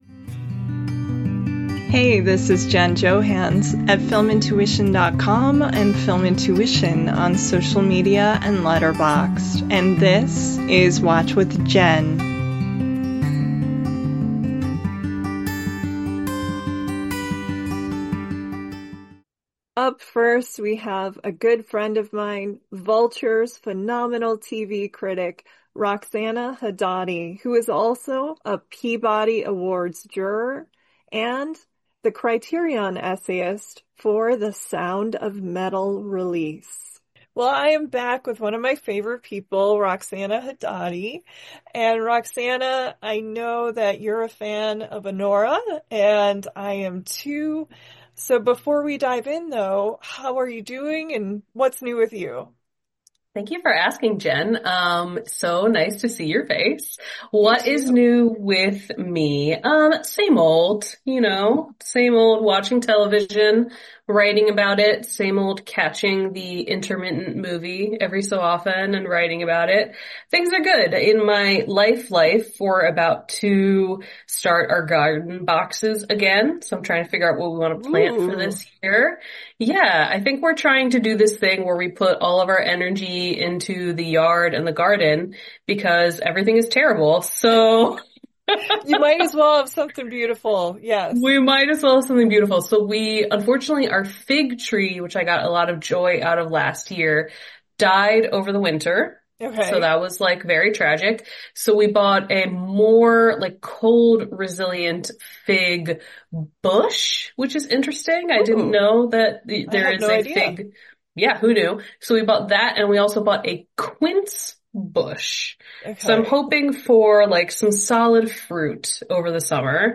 Theme Music: Solo Acoustic Guitar